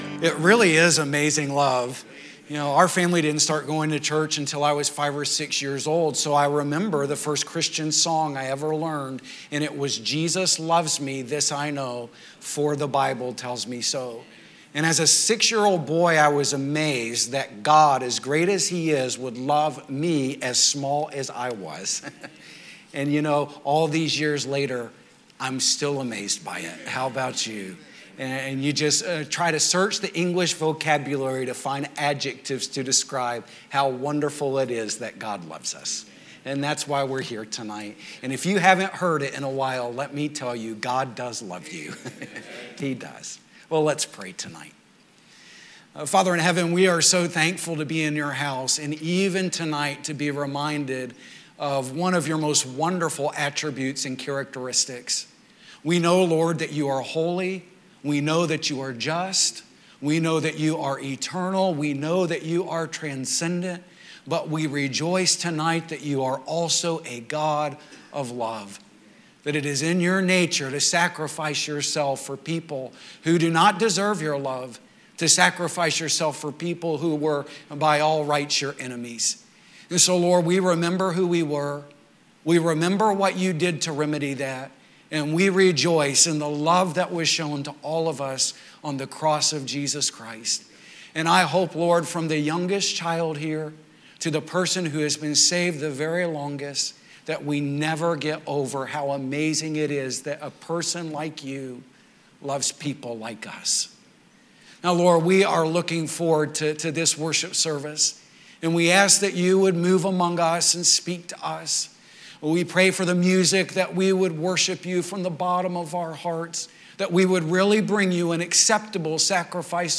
Sunday Evening
Sermons